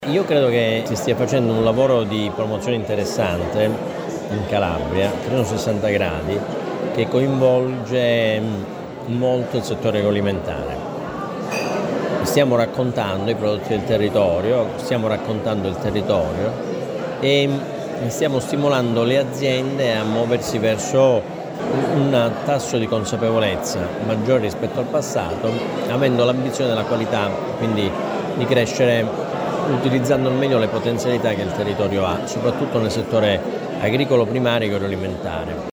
L’evento, che ha visto la partecipazione del sindaco Giacinto Mannarino e dell’assessore regionale all’Agricoltura Gianluca Gallo, ha sottolineato l’importanza della promozione delle eccellenze gastronomiche locali.
INTERVENTO: GIANLUCA GALLO ASSESSORE REGIONALE ALL’AGRICOLTURA